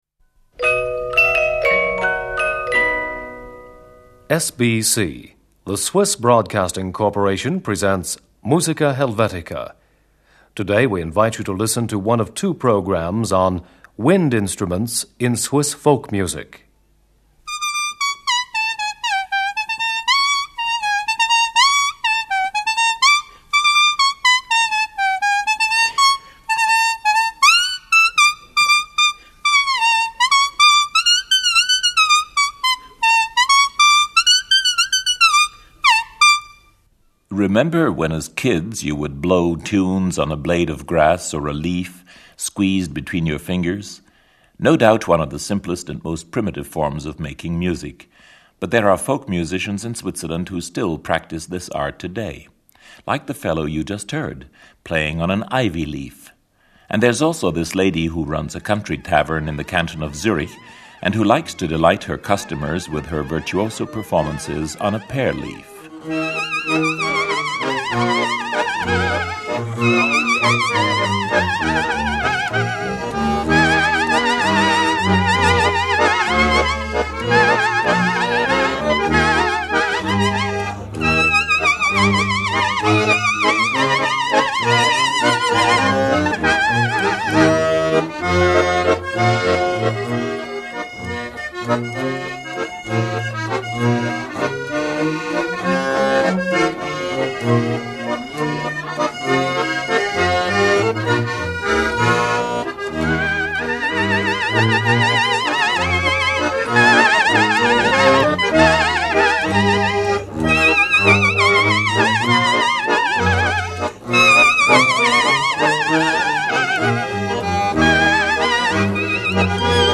Ivy leaf (folk tune).
Pear leaf (folk tune).
Bone pipe.
Bone flute.
Marble fife (goat call).
Schwäbelpfyffe (Swabian fife, with accordion).
Syrinx (panpipes).
Ocarina.
Piccolo flutes with drums.
Natwärisch Pfyffe (Valaisan fife) with drums.